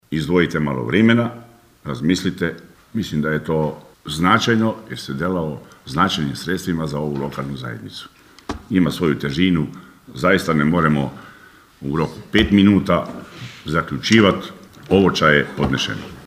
Sjednica Općina Kršan
Nakon burne rasprave oporbeni vijećnici zatražili su pauzu, nakon čega je nezavisni Valdi Runko predložio da se u roku od sedam dana održi radni sastanak i nakon toga ponovo sazove sjednica Vijeća: (